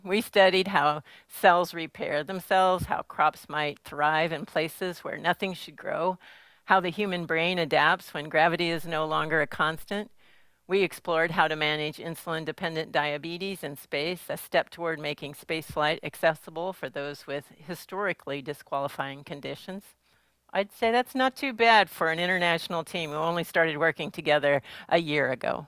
Whitson says she wanted to create not only a high-performing crew, but also one with bonds that would last a lifetime. During today's (Friday) Axiom Space news conference to "debrief" the mission, she touted how the four-member crew performed more than 60 experiments while aboard the orbiting research lab that represented the scientific interests of more than 30 nations.